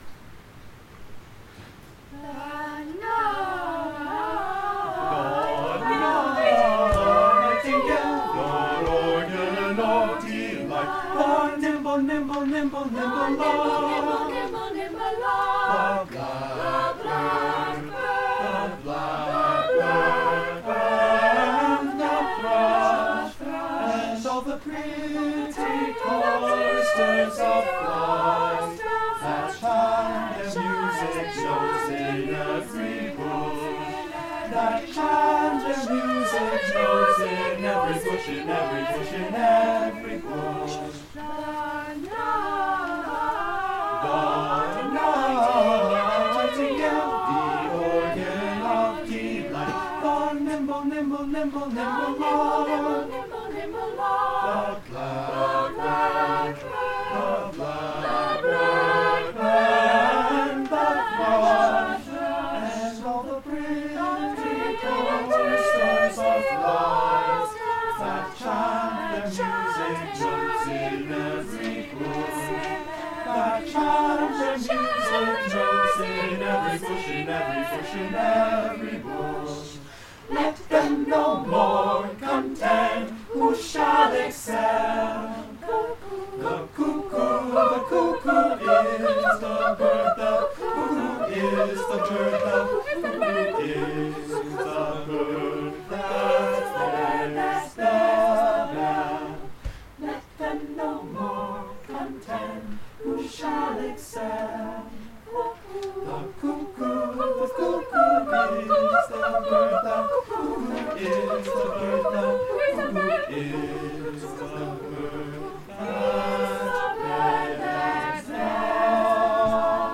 the students
Madrigals